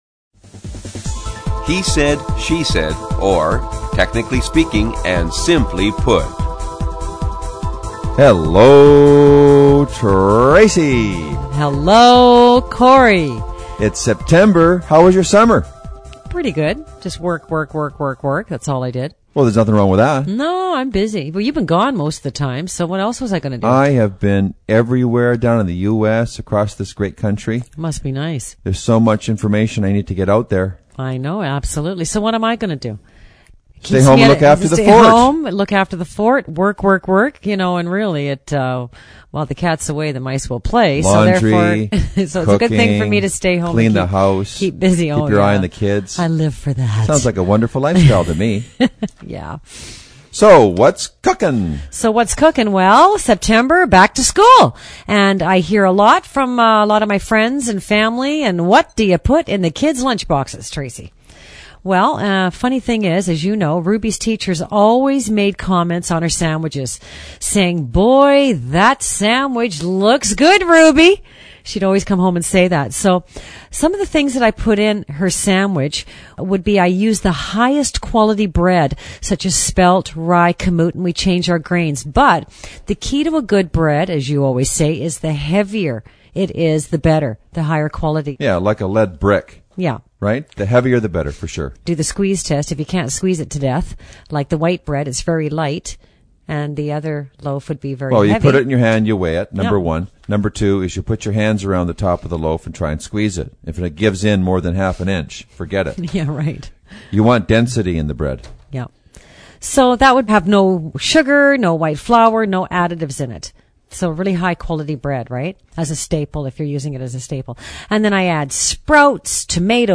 spontaneous and humorous dialog